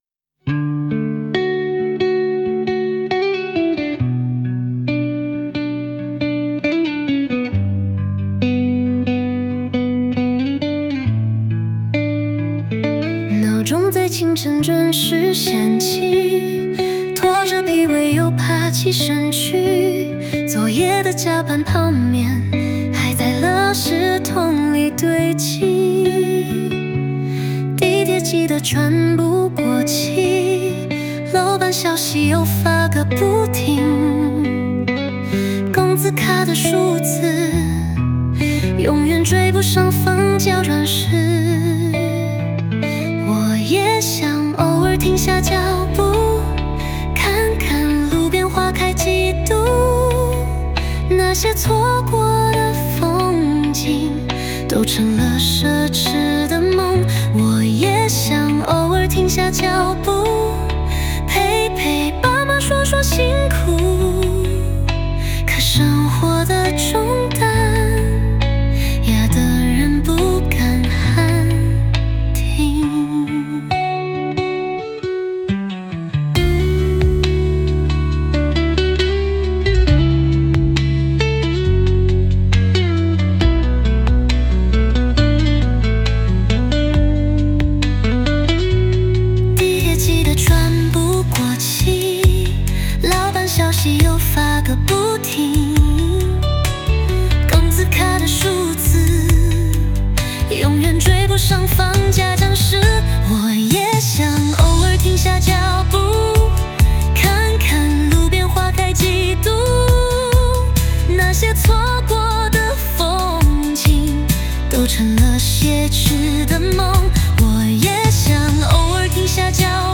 Ps：在线试听为压缩音质节选